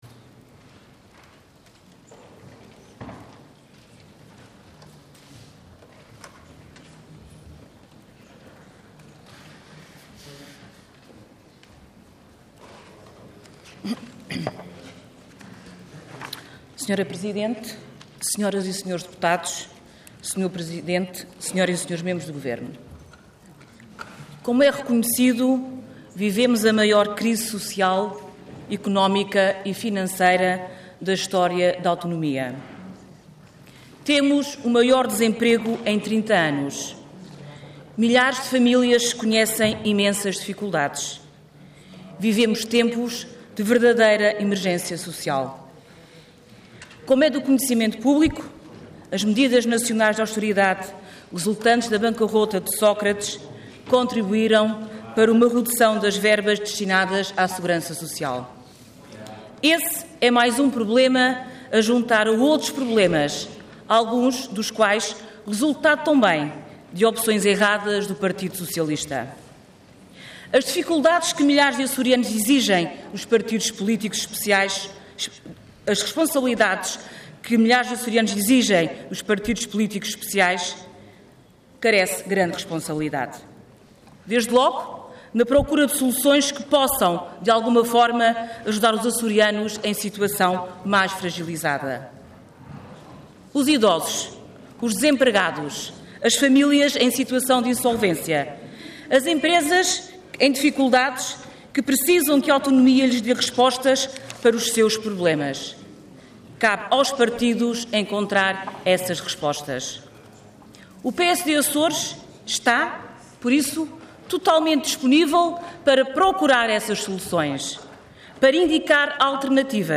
Intervenção Intervenção de Tribuna Orador Aida Amaral Cargo Deputada Entidade PSD